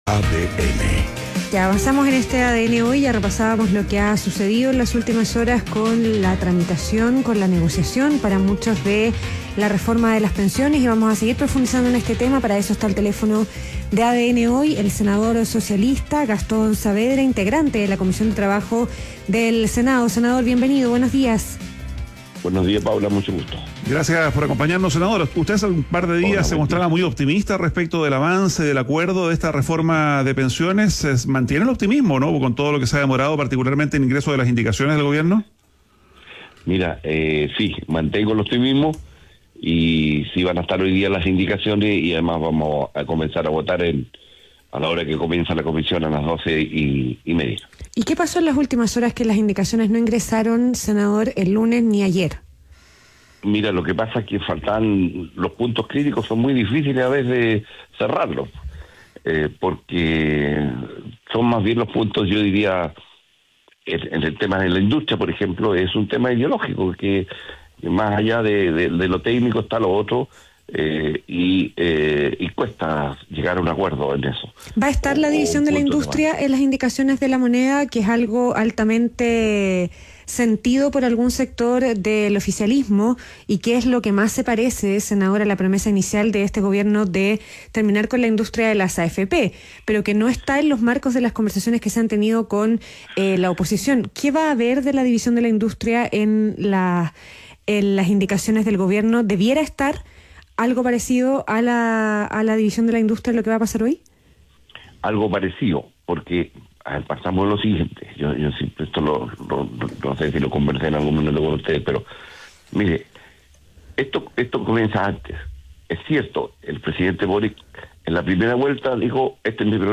ADN Hoy - Entrevista a Gastón Saavedra, senador del PS